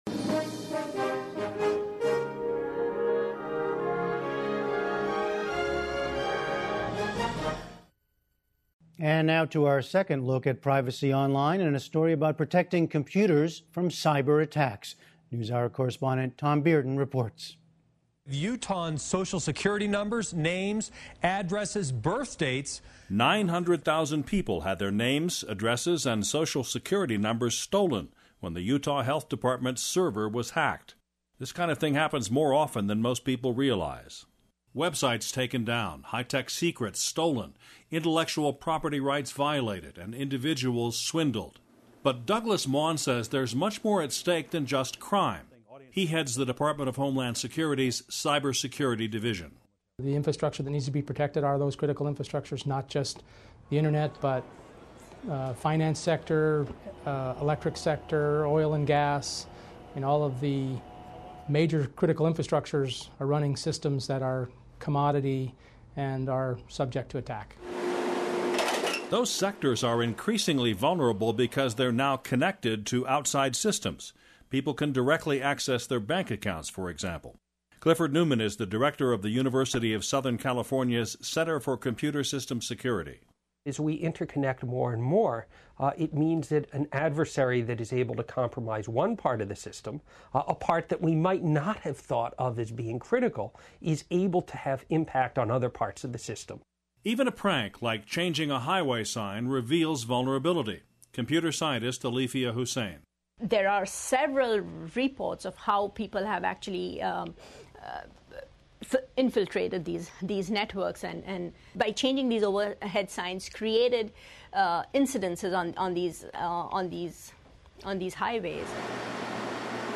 news report (pbs)